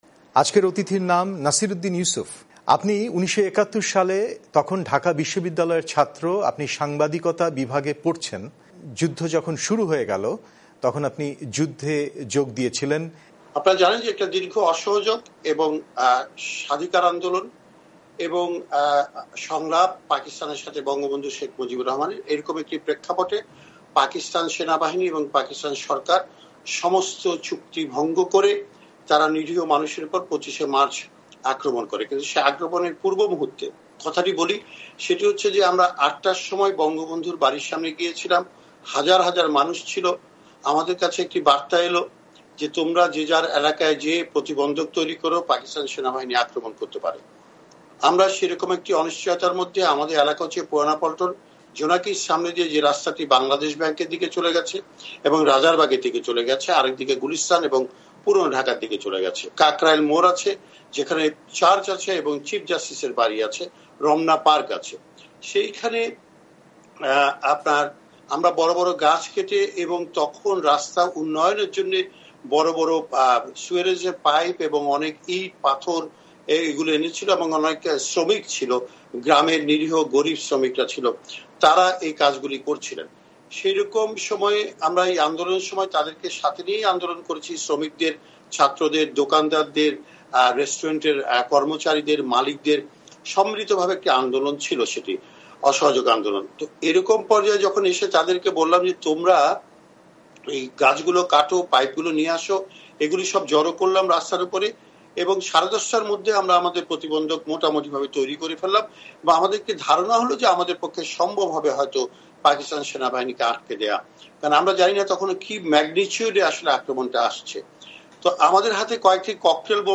শুনুন নাসির উদ্দিন ইউসুফের সাক্ষাৎকার